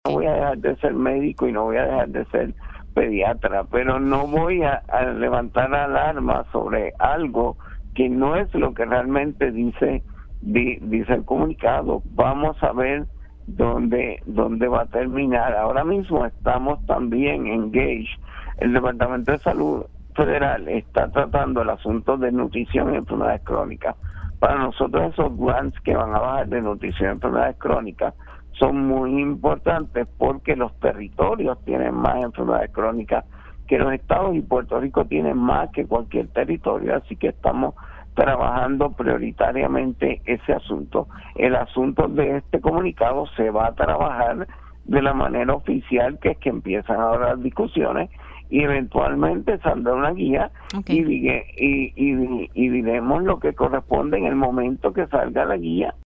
Por otro lado, el secretario del Departamento de Salud, Víctor Ramos indicó que que no levantará alarma contra las expresiones del presidente de los Estados Unidos y aseguró que
218-VICTOR-RAMOS-SEC-SALUD-NO-LEVANTARA-BANDERA-SOBRE-EXPRESIONES-DE-TRUMP-QUE-VINCULAN-ACETAMINOFEN-CON-EL-AUTISMO.mp3